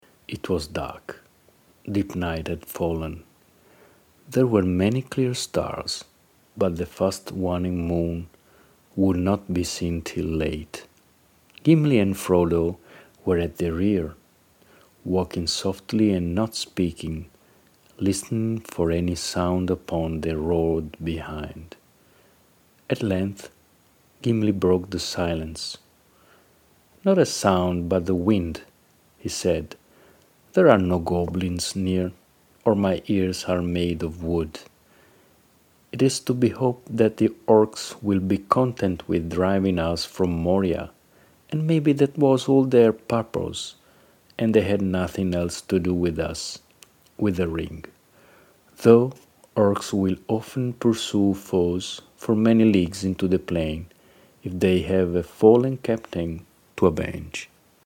La mia voce è amichevole, informale ma anche professionale.
Amichevole, professionale e business-oriented
Sprechprobe: Sonstiges (Muttersprache):